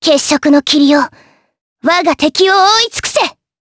Lewsia_A-Vox_Skill4_jp.wav